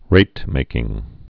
(rātmākĭng)